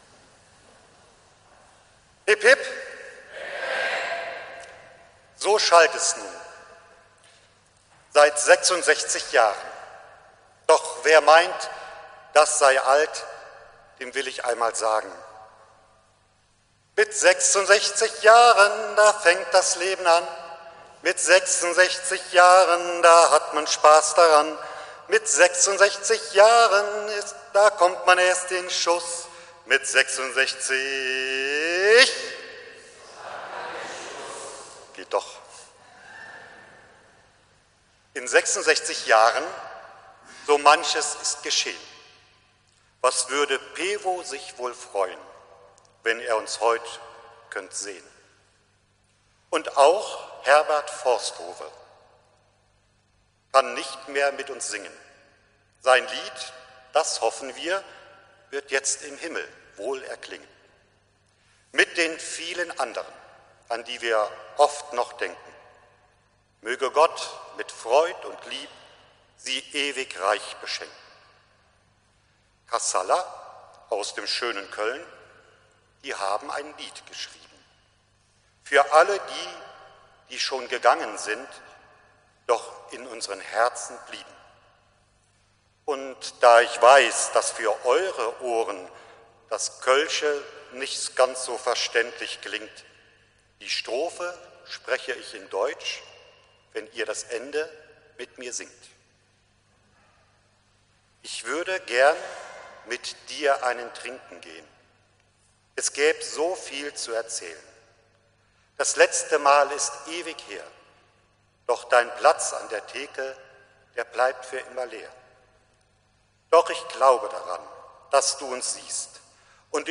Reimpredigt 2020
reimpredigt-2020